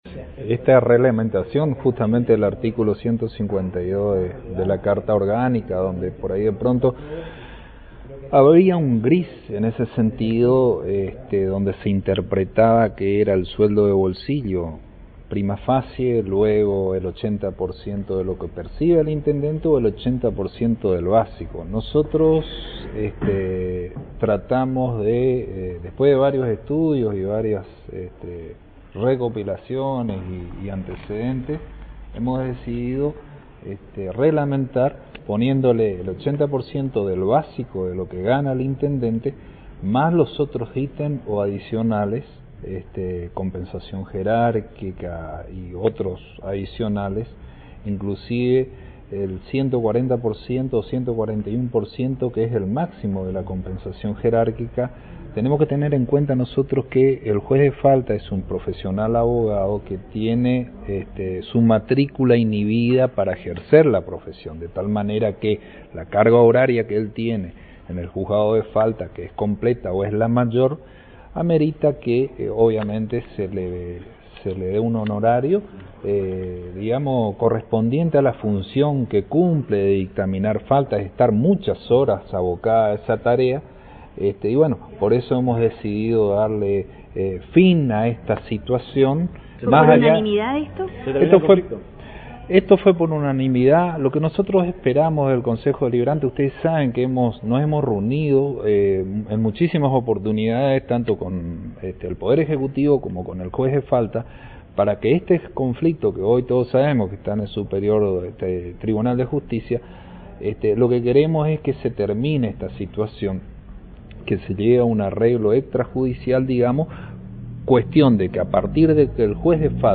Audio: Ariel Chaves / Presidente Concejo Deliberante